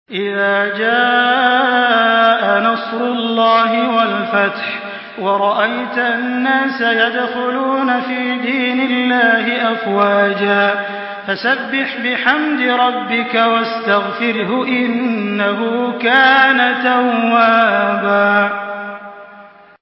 Surah An-Nasr MP3 in the Voice of Makkah Taraweeh 1424 in Hafs Narration
Murattal